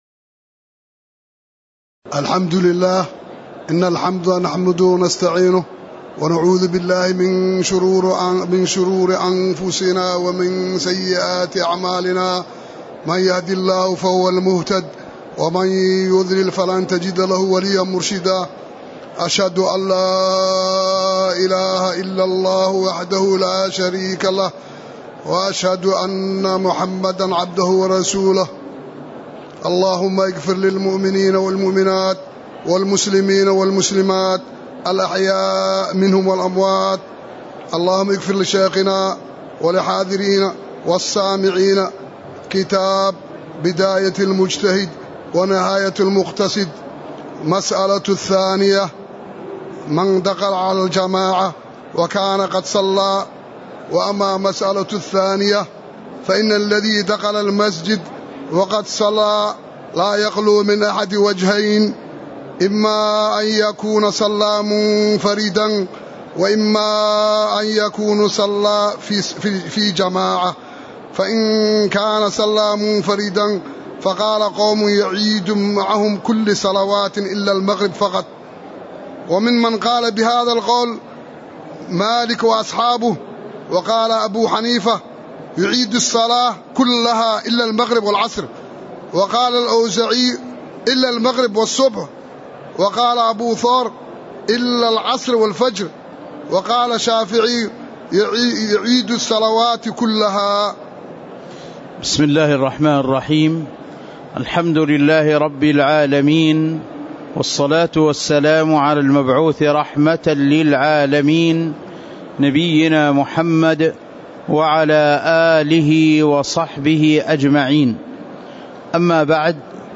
تاريخ النشر ٧ رجب ١٤٤١ هـ المكان: المسجد النبوي الشيخ